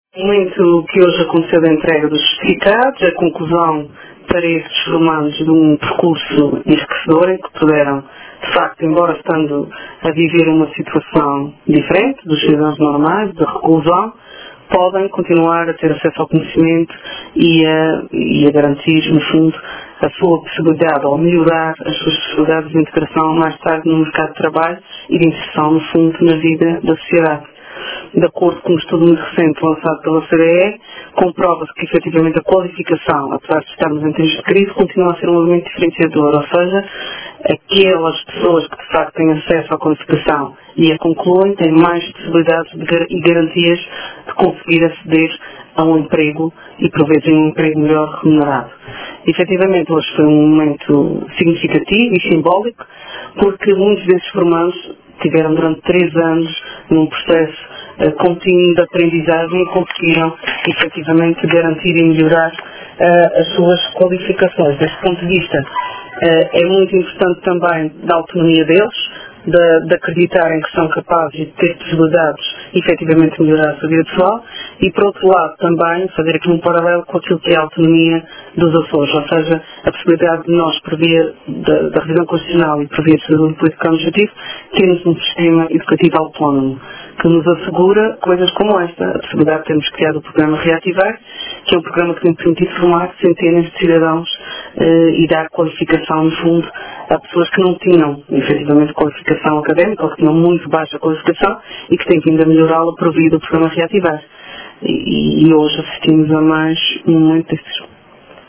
A Secretária Regional da Educação e Formação, Cláudia Cardoso, esteve presente hoje na entrega de diplomas aos reclusos do Estabelecimento Prisional de Angra do Heroísmo que concluíram diversos níveis de escolaridade, enquanto cumpriam pena.
Falando aos jornalistas, a governante disse que este momento de entrega dos certificados “é a conclusão de um percurso enriquecedor, em que os formandos puderam, embora estando em reclusão, continuar a ter acesso ao conhecimento e a melhorar as suas possibilidades de integração, mais tarde, no mercado de trabalho e inserção na vida da sociedade.